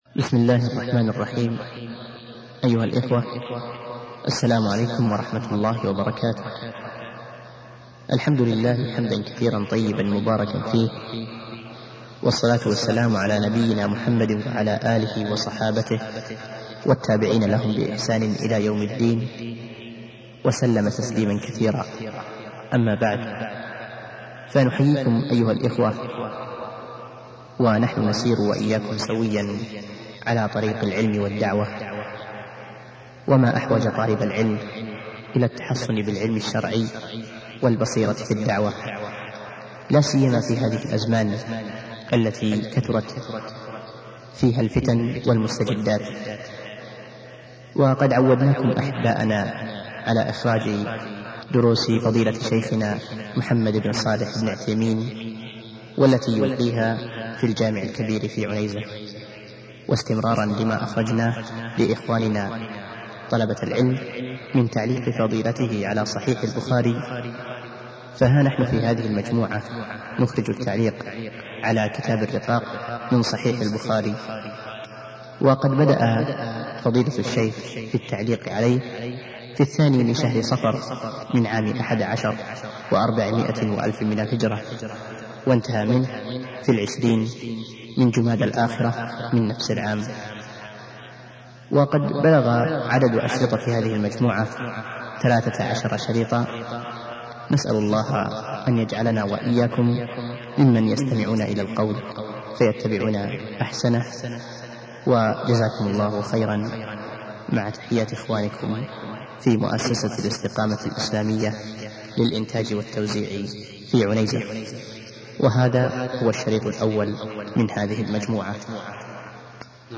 الدرس الأول -التعليق على كتاب الرقاق و القدر من صحيح البخاري - فضيلة الشيخ محمد بن صالح العثيمين رحمه الله